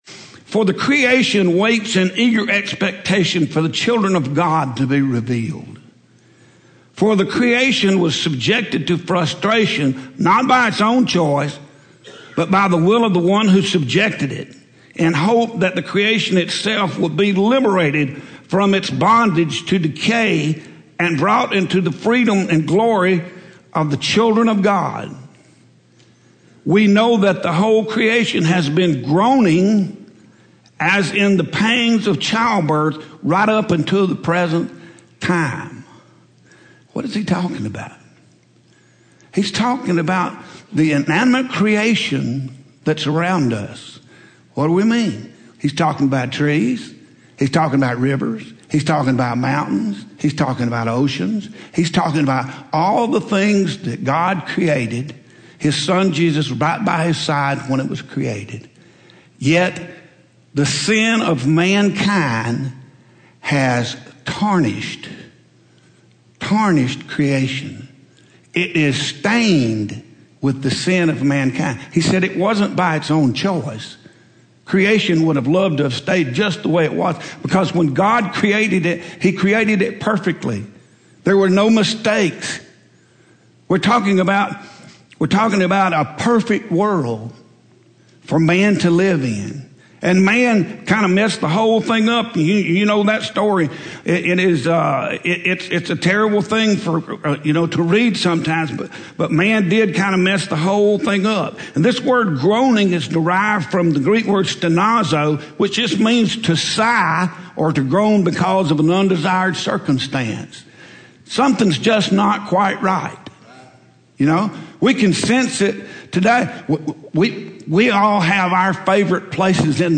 Home › Sermons › Groaning And Glory